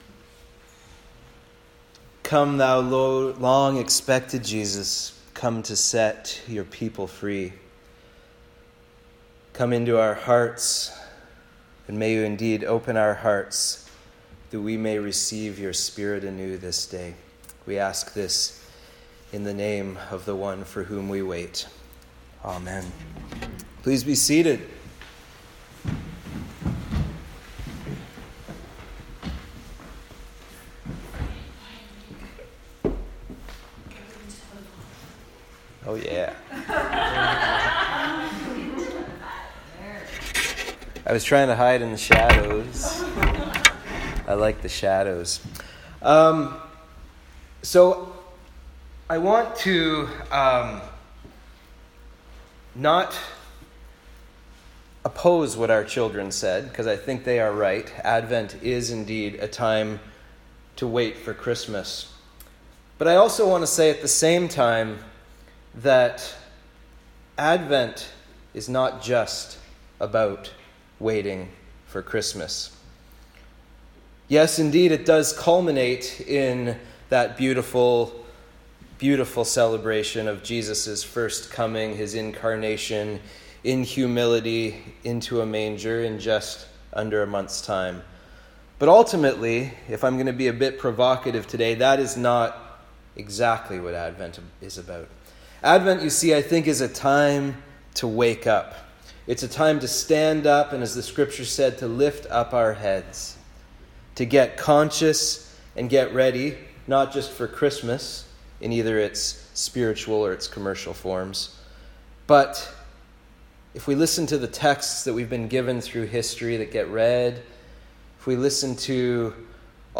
Sermons | the abbeychurch